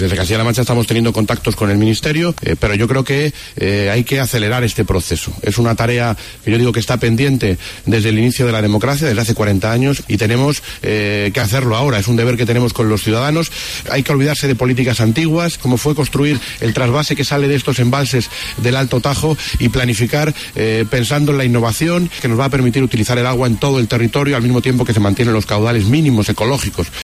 Los pantanos de la cabecera del Tajo, Entrepeñas y Buendía, continúan pese a las lluvias de enero por debajo del 10% de su capacidad. El programa “Agropopular” de COPE se ha desplazado este sábado hasta Entrepeñas para conocer el estado del embalse.
ha entrevistado al consejero de Agricultura, Martínez Arroyo, que ha exigido al Gobierno Central un pacto nacional del agua para distribuir la riqueza.